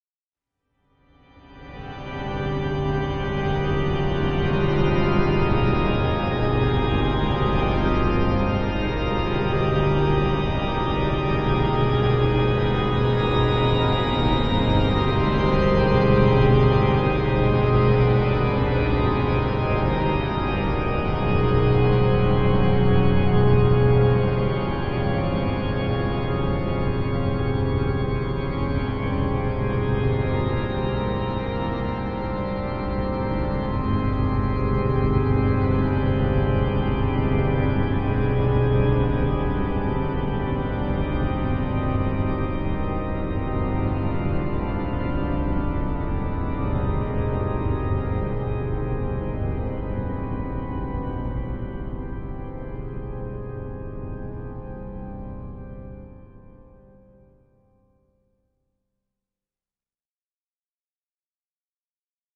Music: Eerie Horror Pipe Organ Melody.